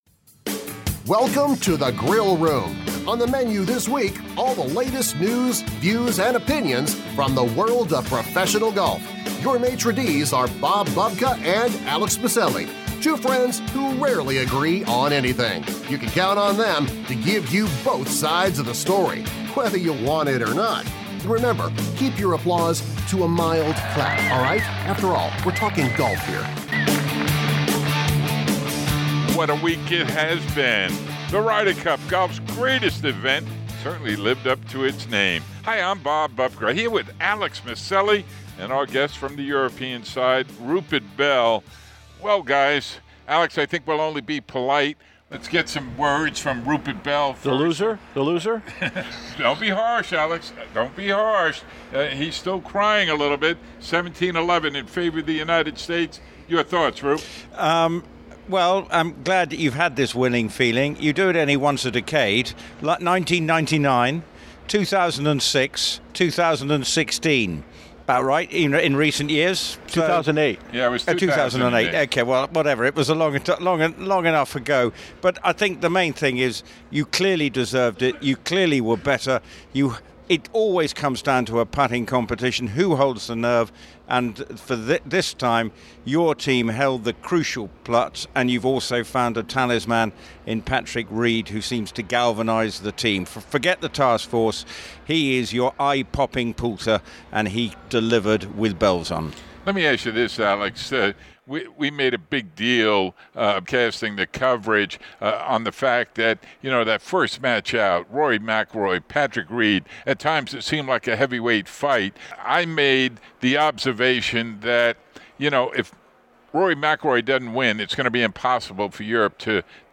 Captain Love, Captain Clarke, Rory, Phil, Rafa, and Patrick Reed describe their experience.